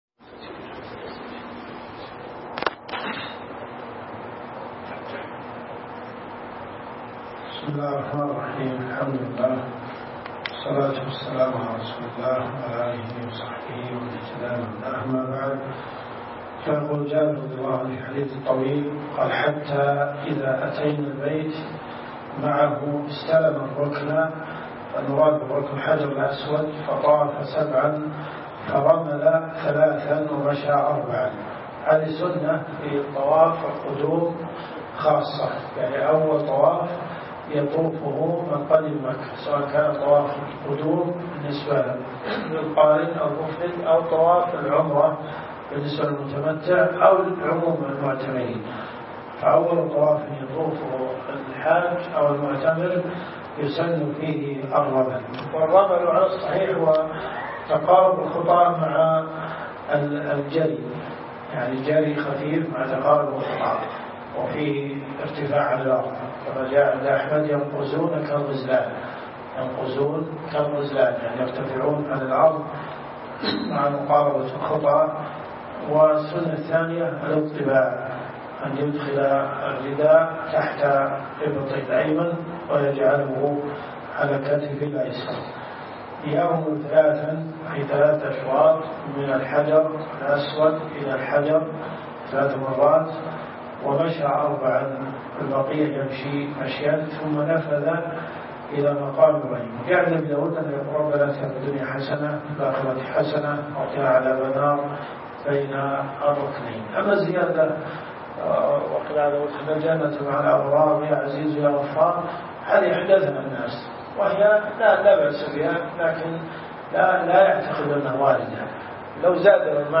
الرئيسية الكتب المسموعة [ قسم الفقه ] > (1) منهج السالكين .